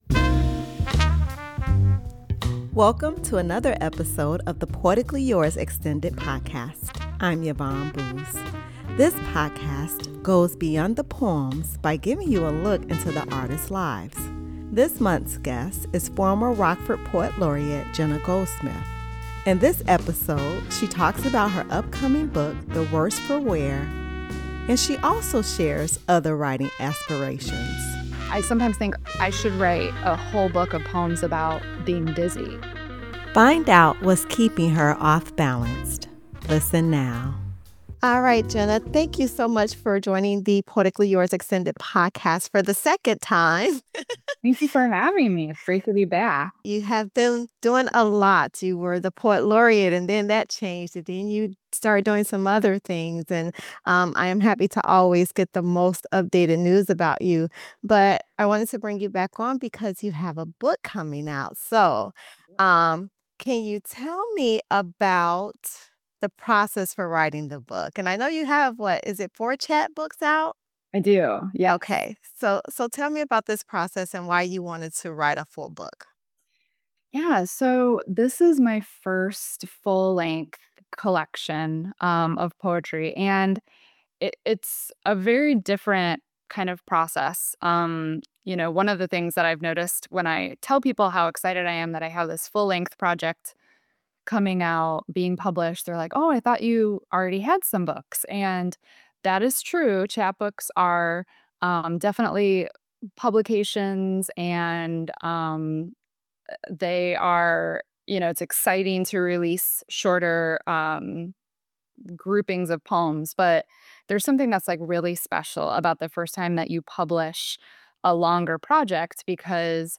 you will hear voices from northern Illinois poets as they share their words about the world around them.